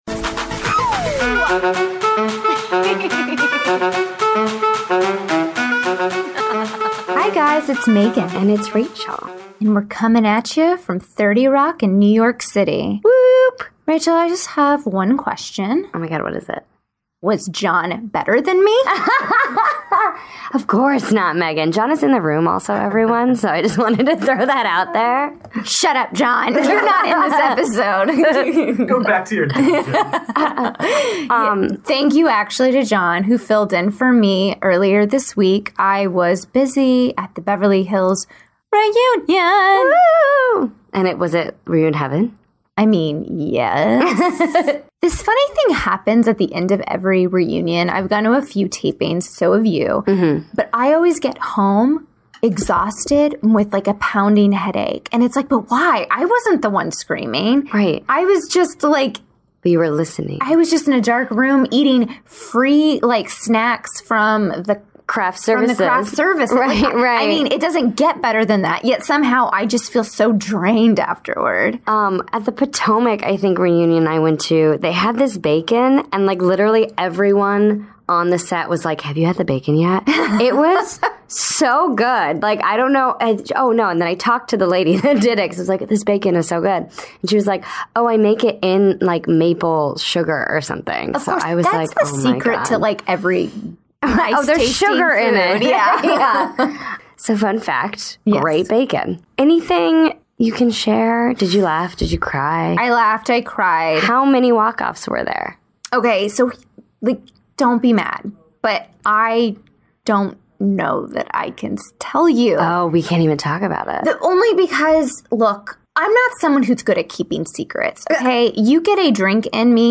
A Traditional Pin Curl (Our Interview with Lala Kent)